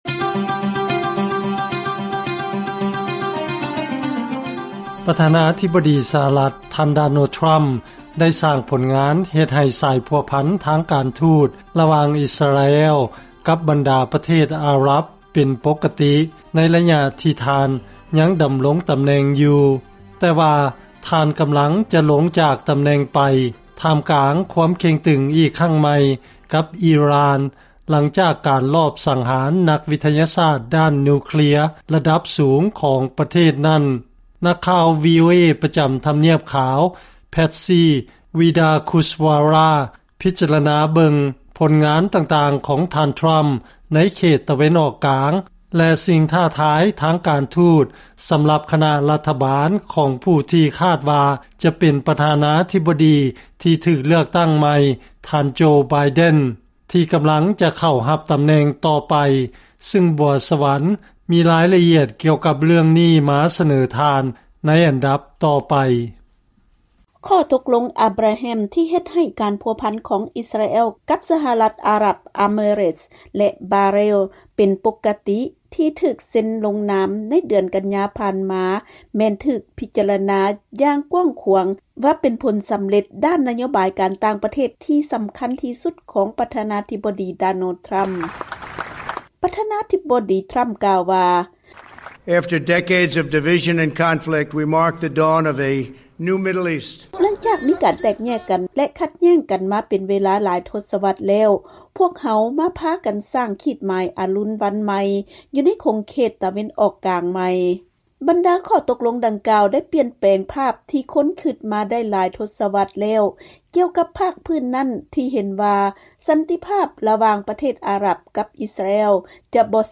ເຊີນຟັງລາຍງານກ່ຽວກັບນະໂຍບາຍການຕ່າງປະເທດຂອງ ສຫລ ຕໍ່ຂົງເຂດຕາເວັນອອກກາງ